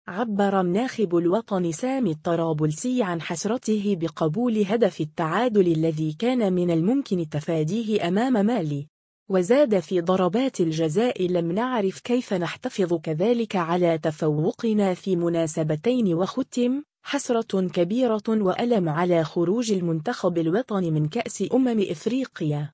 عبّر الناخب الوطني سامي الطرابلسي عن حسرته بقبول هدف التعادل الذي كان من الممكن تفاديه أمام مالي.